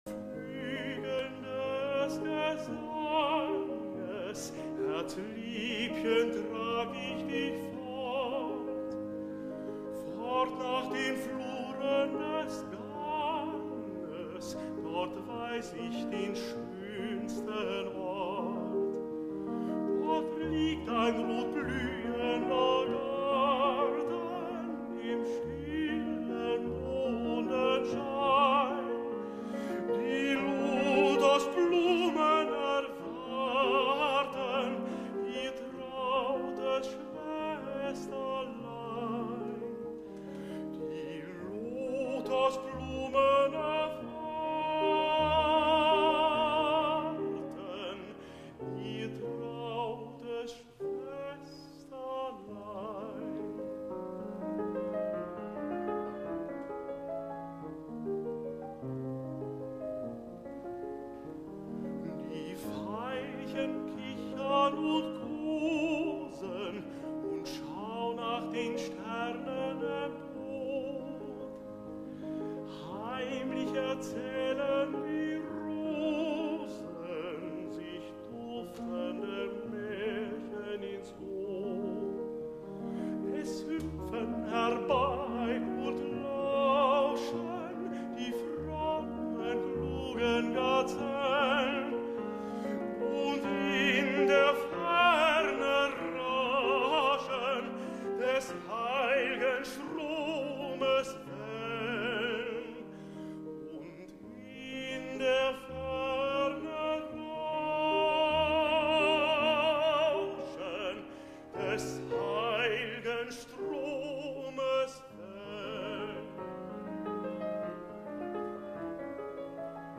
La versió per a mi quasi referencial del tot, deguda a Victoria de los Ángeles la vaig desestimar per estar acompanyada en un preciós arranjament orquestral que semblava que donava prioritat a l’elecció, i per això em vaig estimar més que totes les versions fossin acompanyades a piano.